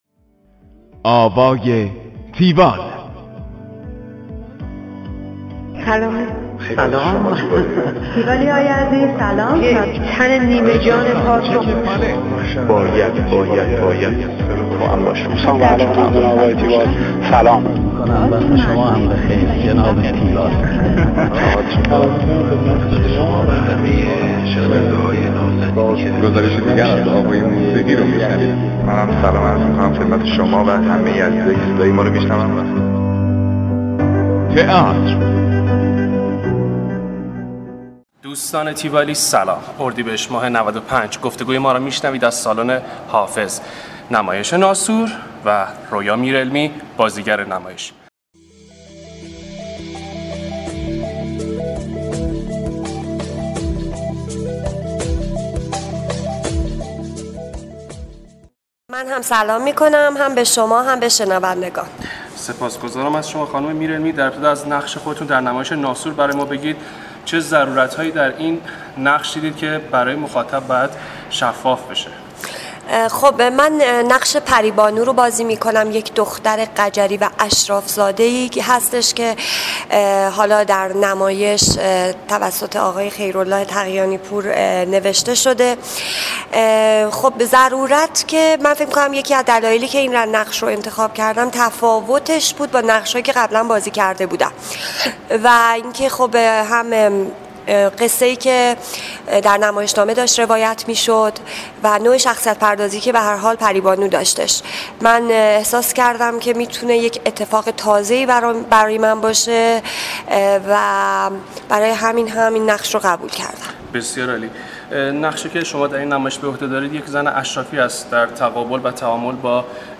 tiwall-interview-royamirelmi.mp3